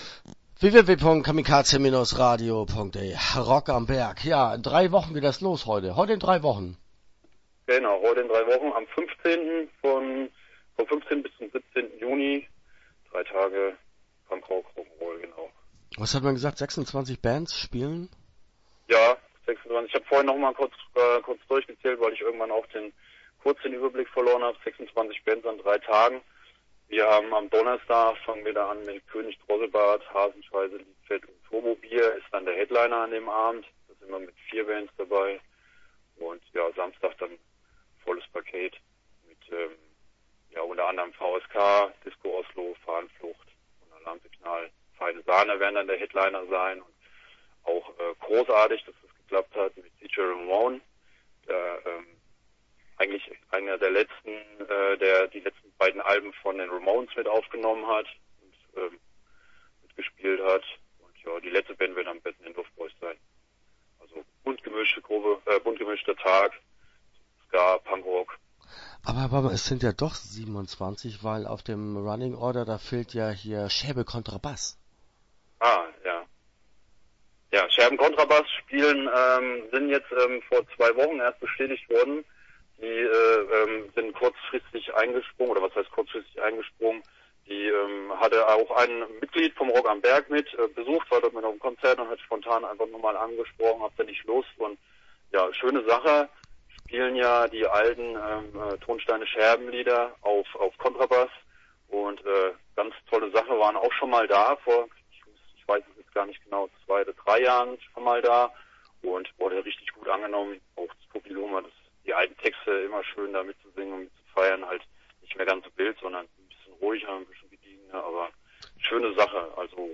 Rock am Berg - Merkers - Interview Teil 1 (7:34)